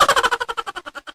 babylaugh.wav